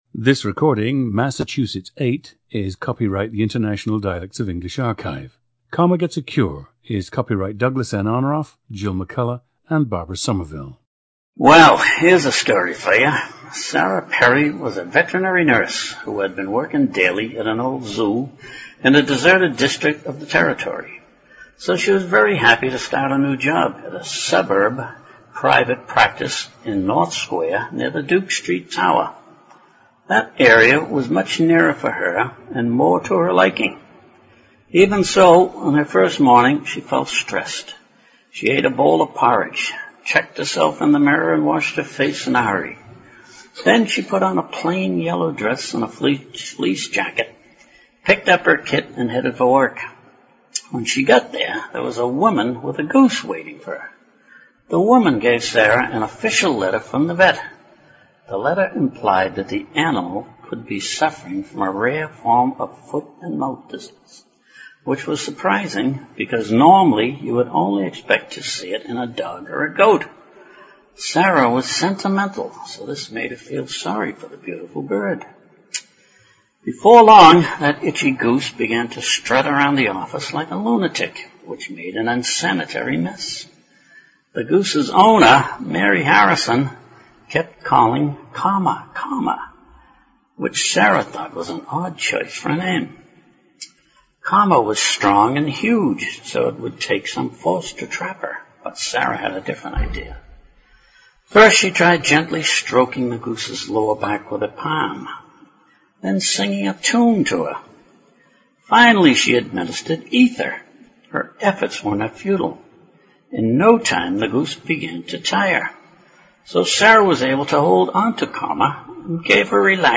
PLACE OF BIRTH: Boston (Dorchester neighborhood)
GENDER: male
OTHER INFLUENCES ON SPEECH:
He grew up near the “Southie” border, in a mostly Irish neighborhood.
• Recordings of accent/dialect speakers from the region you select.